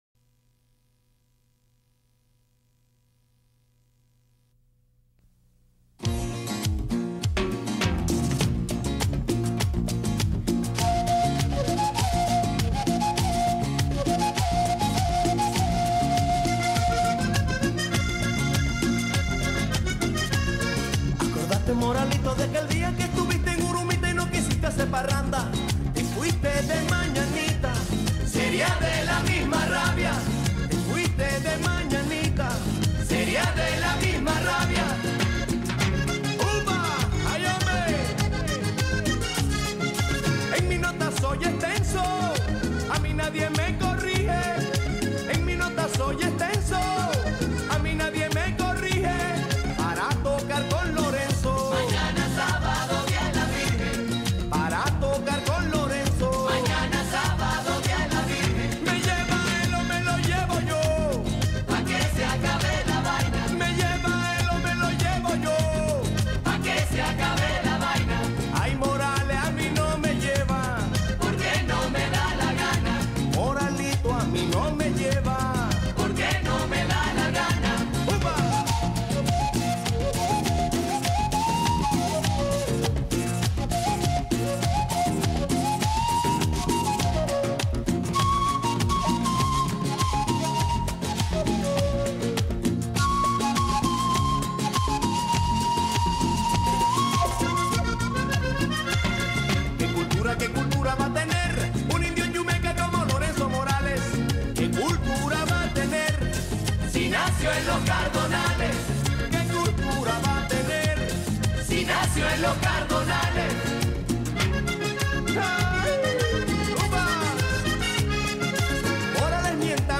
teniendo como cortina las notas de un acordeón.